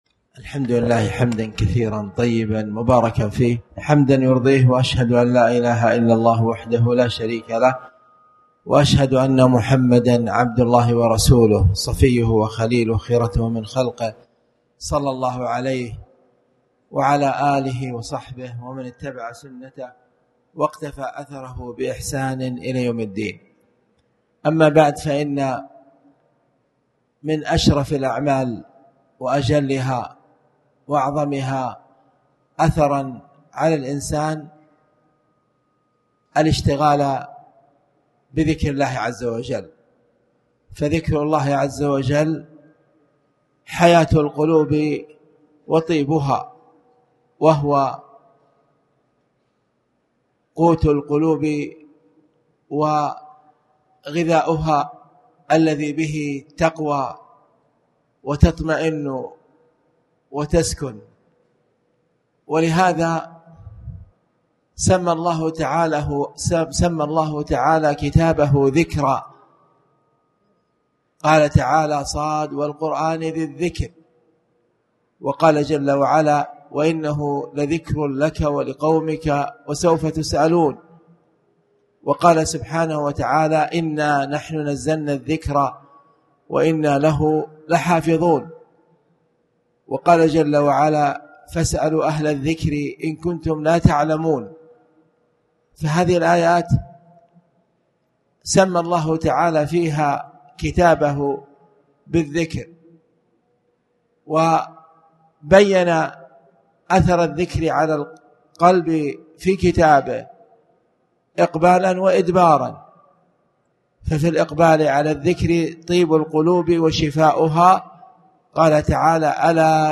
تاريخ النشر ١٨ محرم ١٤٣٩ هـ المكان: المسجد الحرام الشيخ
18mhrm-bab-sfh-alslah-bad-alfjr.mp3